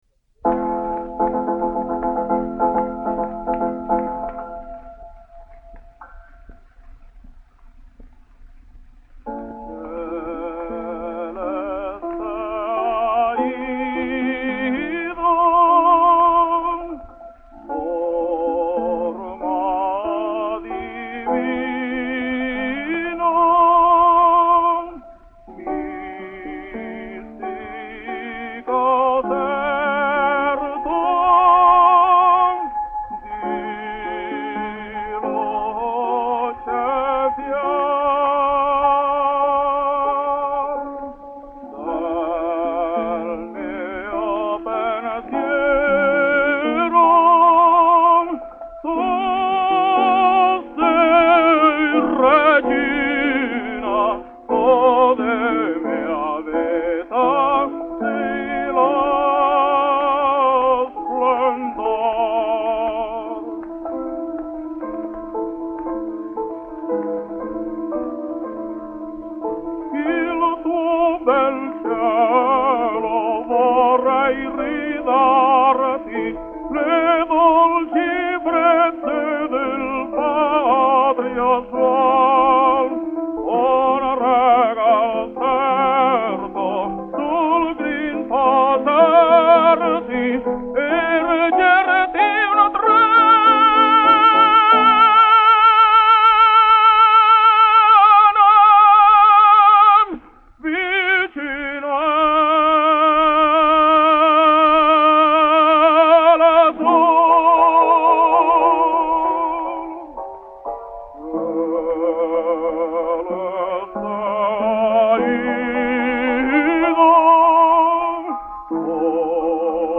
Spanish Tenor.
Yet this is no miniature performance but is full throttled and noble.
The minute care with which every note and every phrase is weighed.